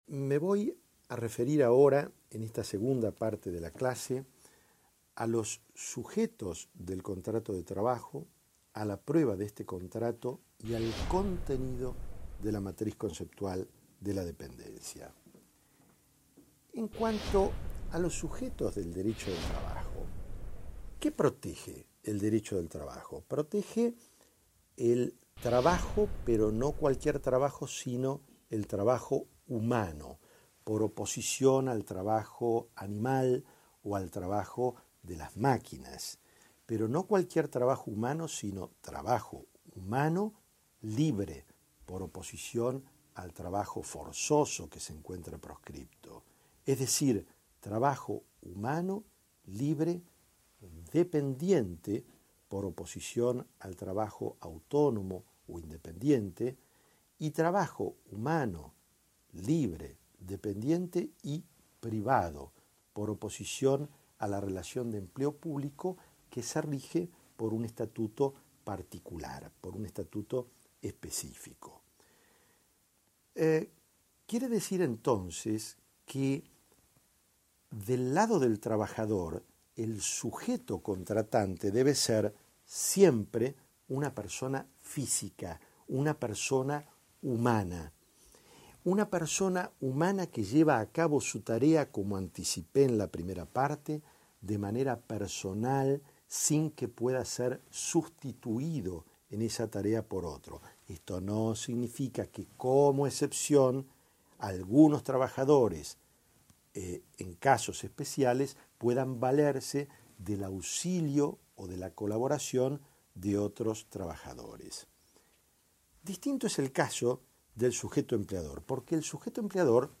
Audio de la clase Descargar clase en formato MP3 MP3 � Anterior - Inicio - Siguiente »